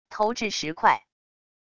投掷石块wav音频